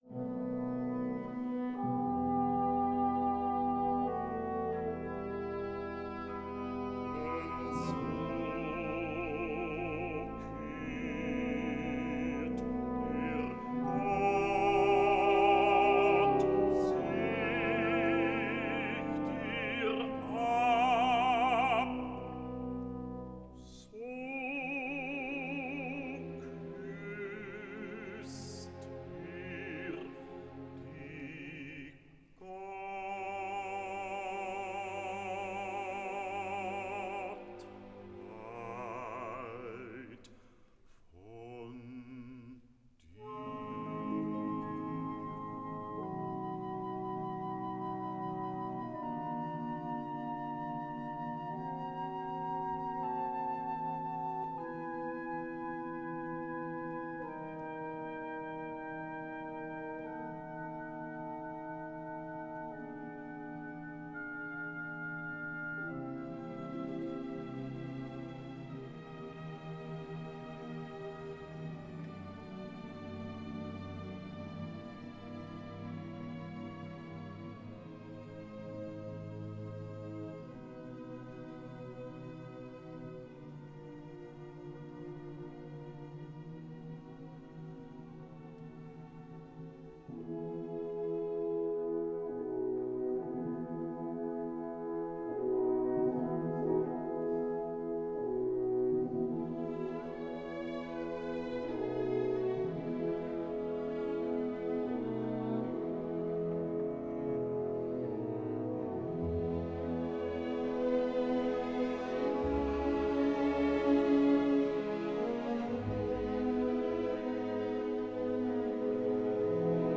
registrazione in studio
orchestra